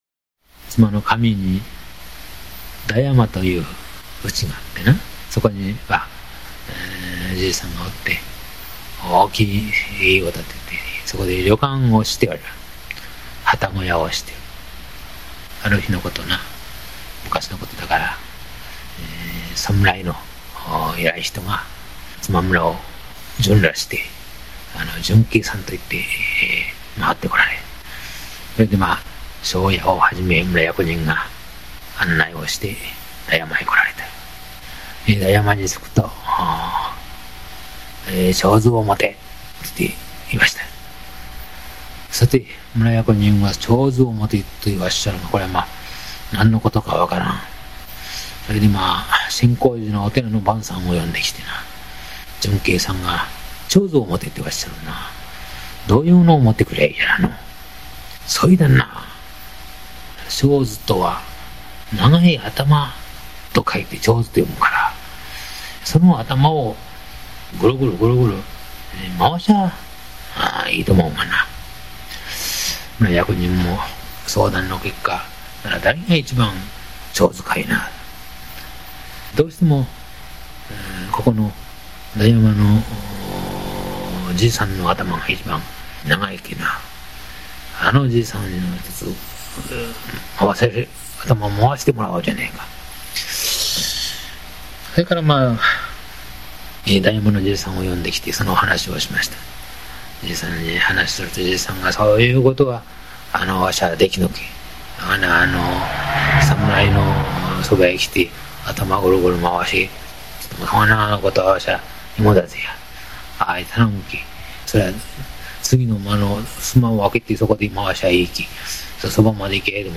語り手　男性・明治45年（1912）生
語り手はあらかじめ録音しておかれたカセットテープを、提供してくださったが、その中にこの話もあった。